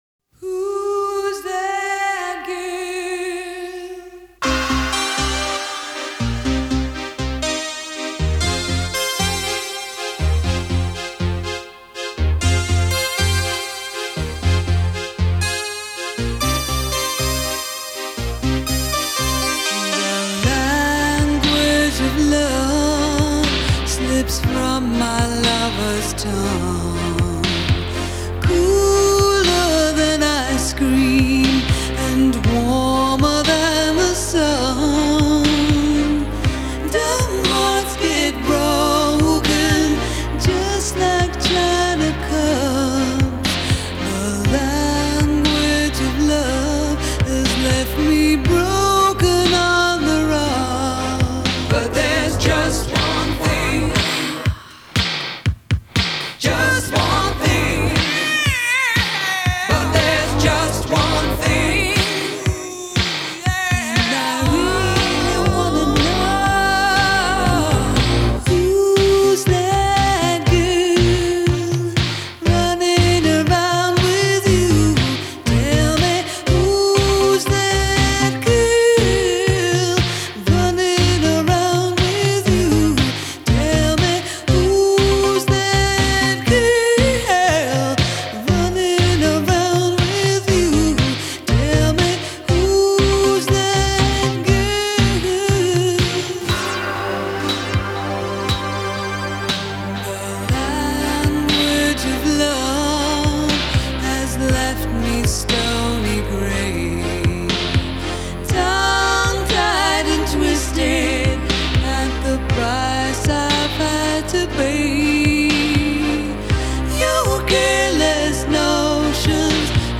синти-поп-дуэт